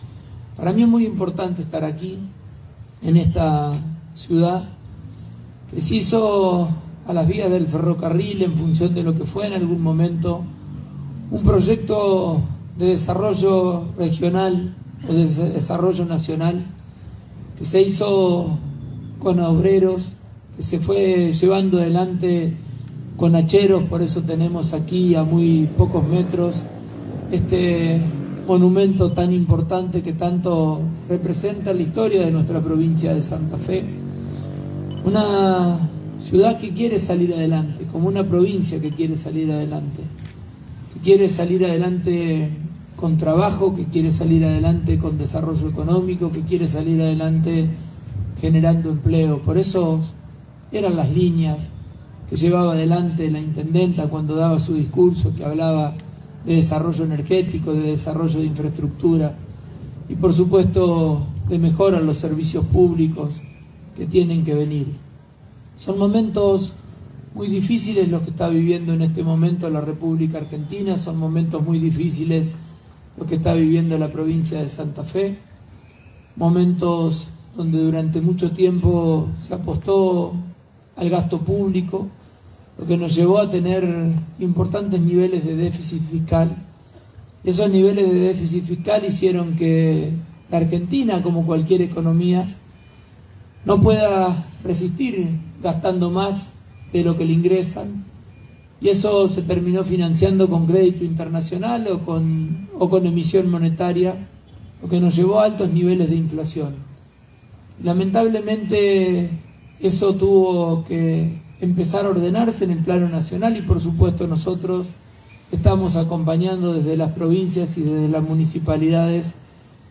En el marco del acto oficial en conmemoración de un nuevo aniversario de la fundación de Vera y de la declaratoria de está como ciudad, estuvo presente el gobernador de la provincia, Maximiliano Pullaro, acompañado por algunos funcionarios de su gabinete.
Maximiliano Pullaro – Gobernador de la provincia de Santa Fe